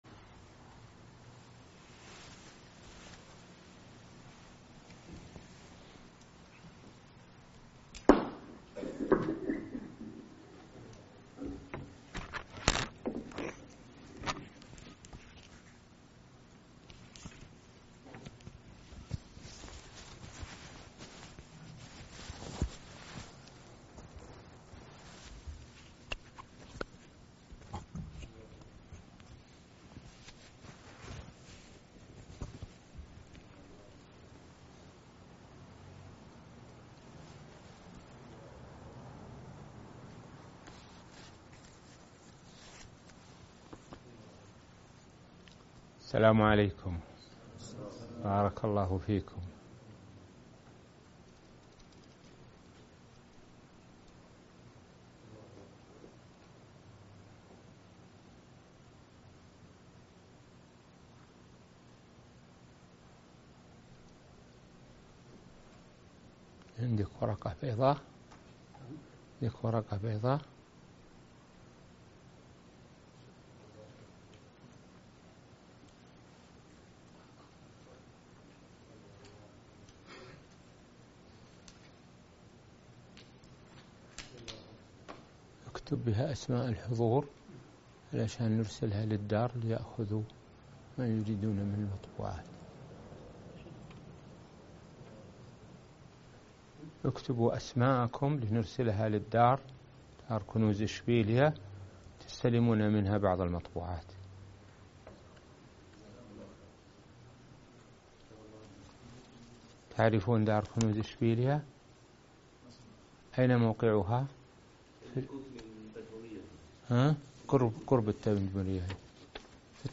الموقع الرسمي لفضيلة الشيخ الدكتور سعد بن ناصر الشثرى | مسائل في تخريج الفروع على الأصول- الدرس (18)